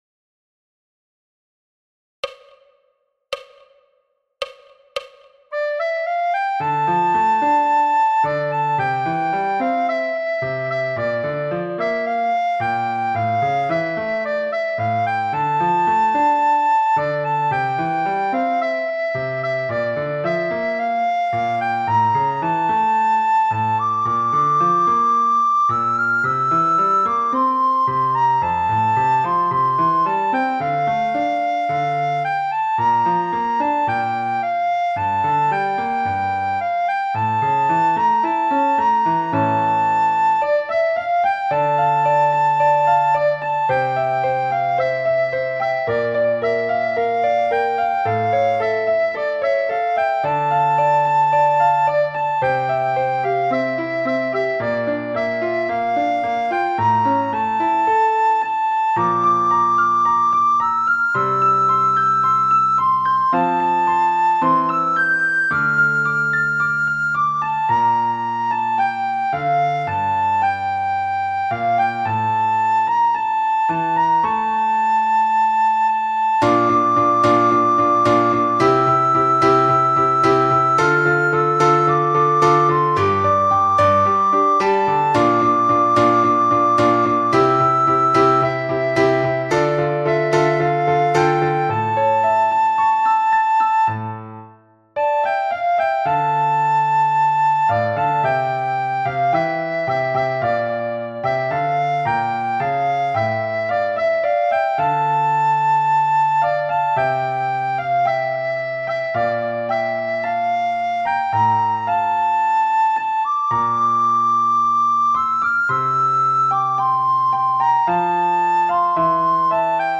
sopraan